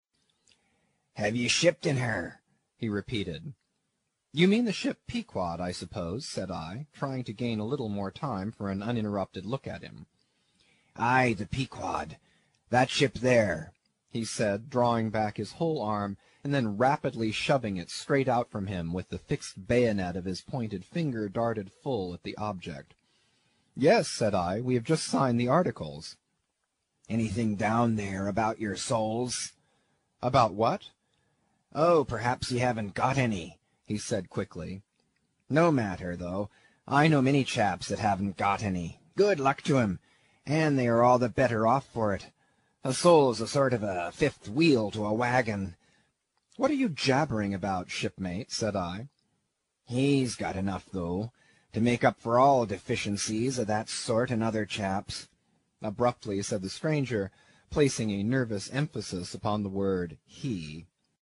英语听书《白鲸记》第104期 听力文件下载—在线英语听力室